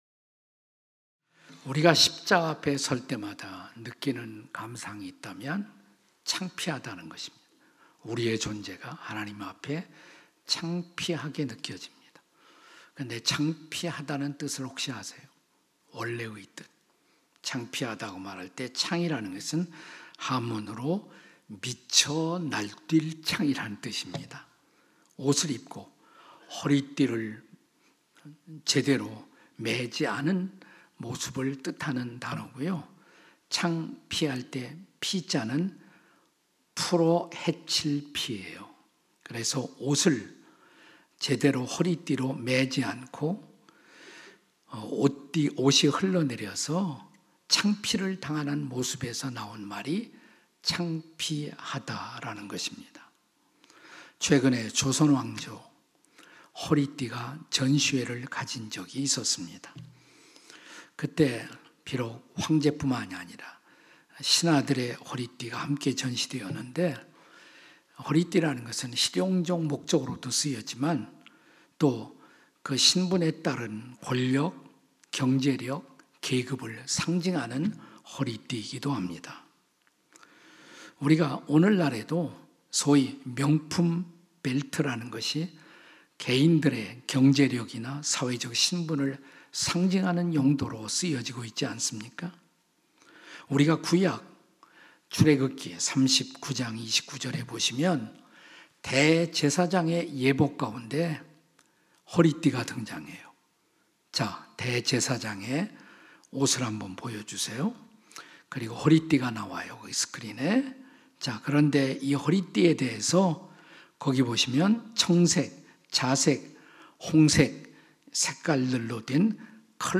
설교 : 주일예배 예레미야 - (11) 썩은 띠의 부활, 어떻게?